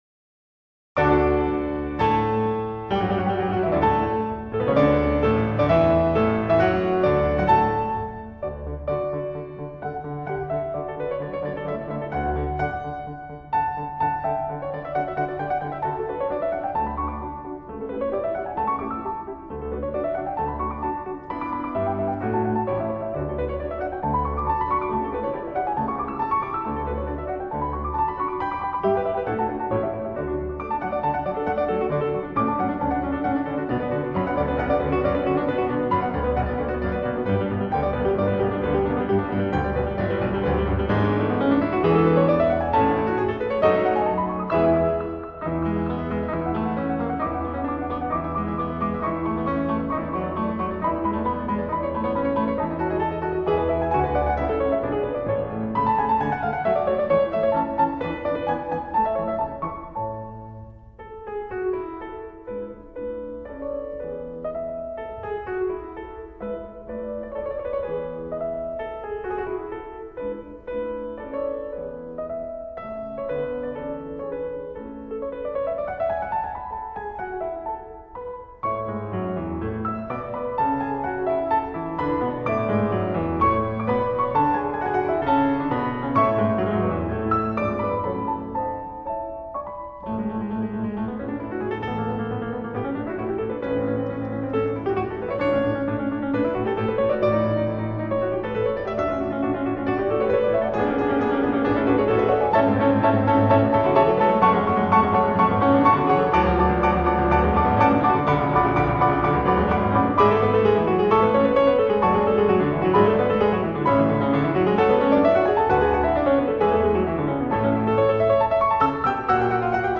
Sonata
mozart-sonata-dos-pianos-k-448-i-allegro-con-brio.mp3